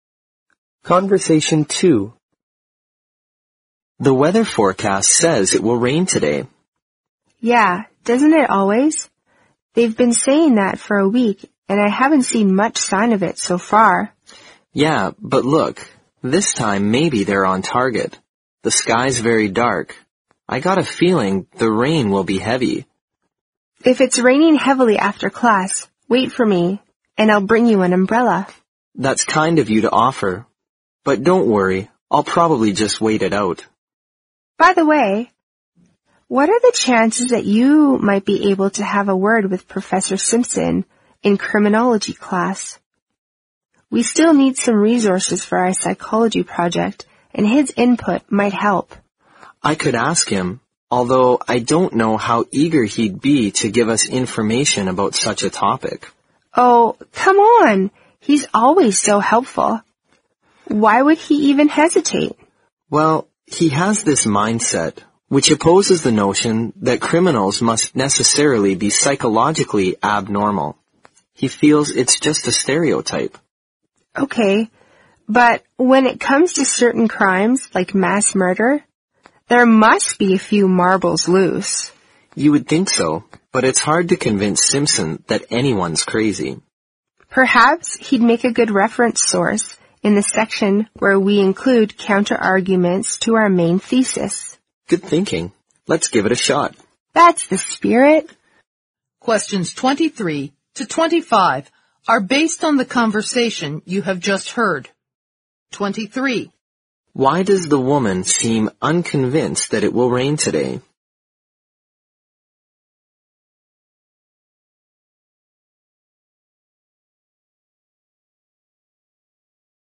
Conversation Two